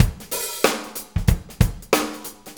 Shuffle Loop 28-09.wav